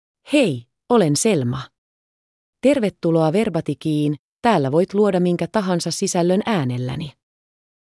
Selma — Female Finnish AI voice
Selma is a female AI voice for Finnish (Finland).
Voice sample
Listen to Selma's female Finnish voice.
Selma delivers clear pronunciation with authentic Finland Finnish intonation, making your content sound professionally produced.